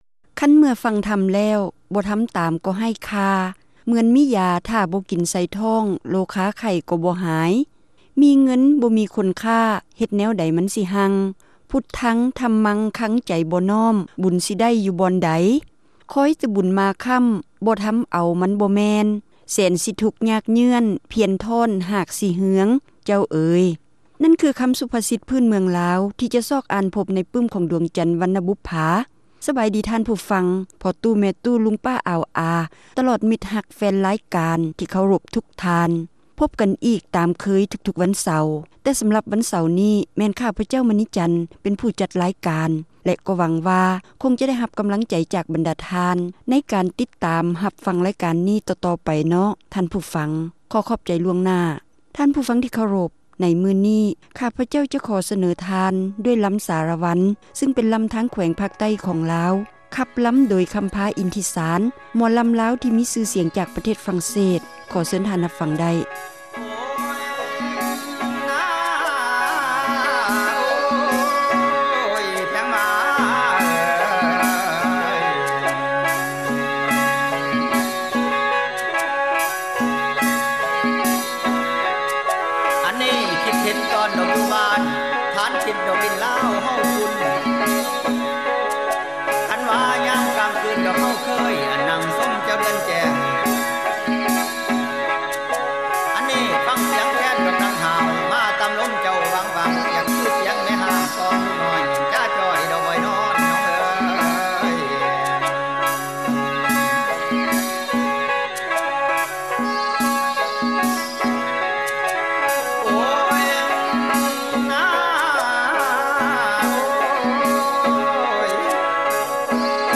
ຣາຍການໜໍລຳ ປະຈຳສັປະດາ ວັນທີ 23 ເດືອນ ກຸມພາ ປີ 2007